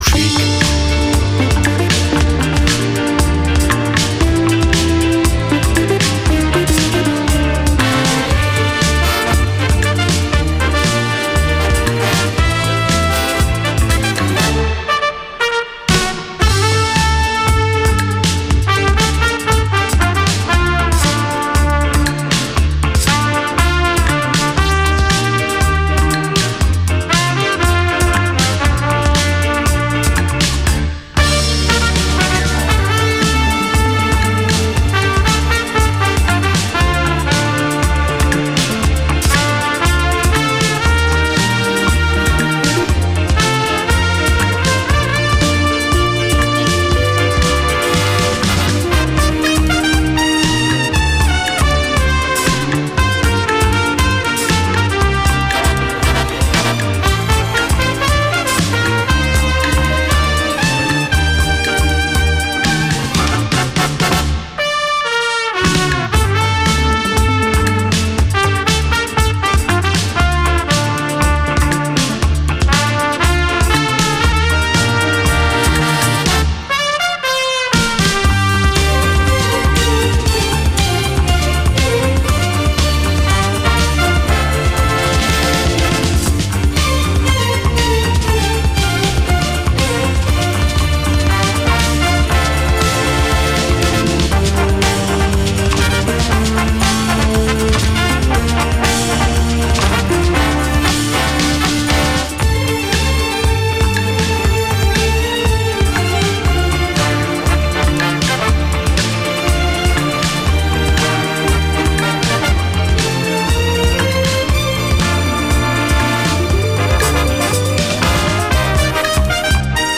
Труба
Запись 15.2.1984   ČSRo Bratislava